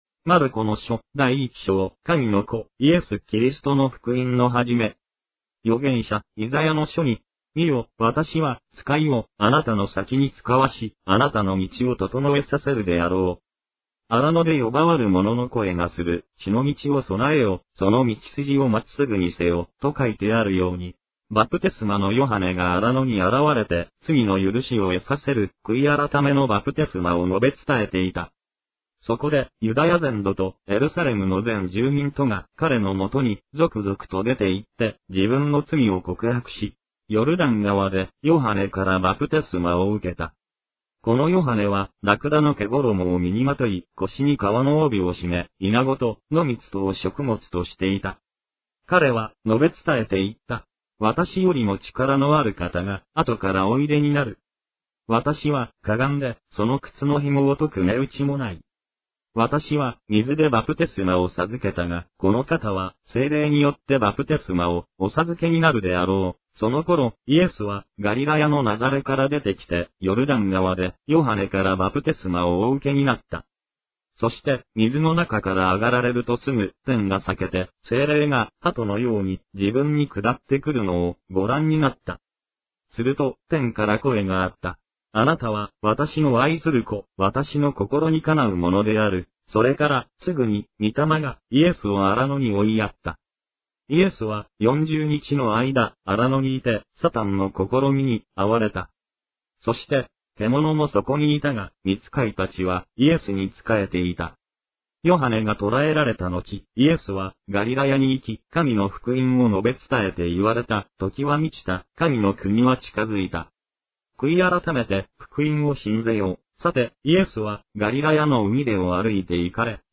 • Voice only Audio Bible reading
japanese-2403-mark-1.mp3